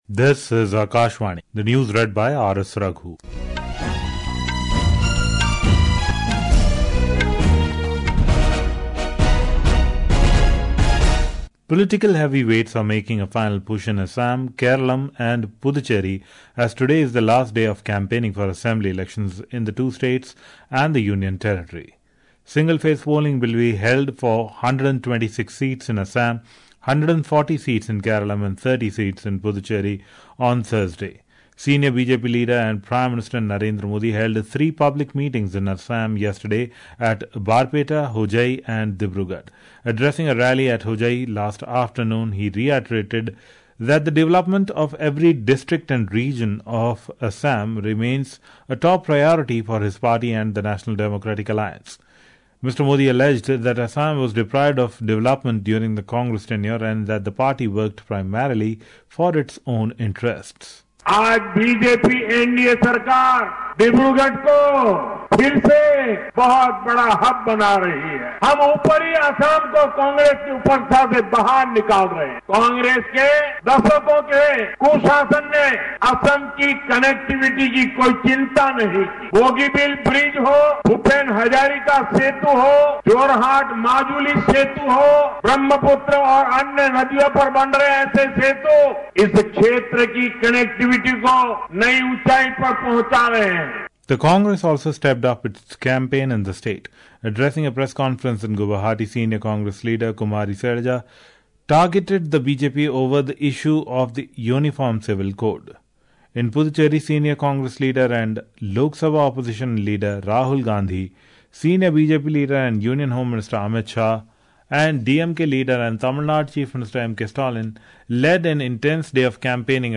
રાષ્ટ્રીય બુલેટિન
प्रति घंटा समाचार